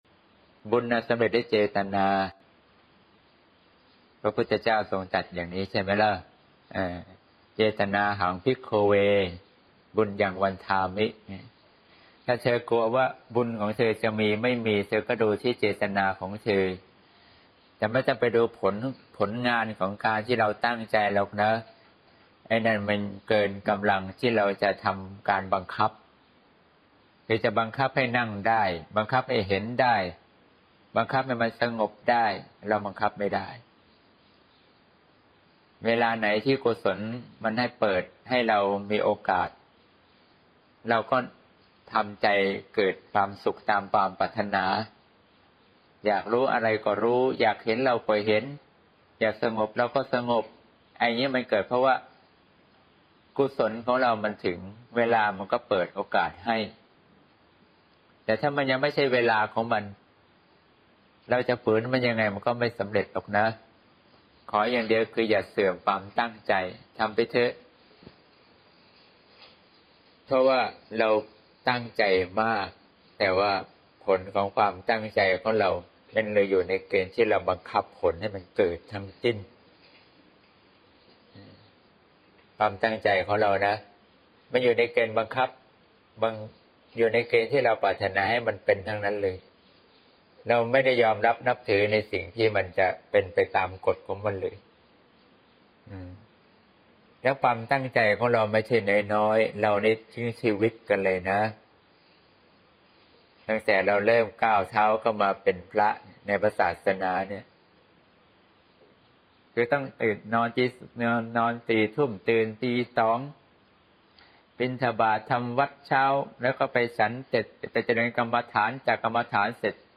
สนทนา (เสียงธรรม ๒๙ มี.ค. ๖๘)